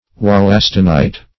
Wollastonite \Wol"las*ton*ite\, n. [After Dr. W. H. Wollaston,